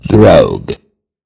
Cries
FROGADIER.mp3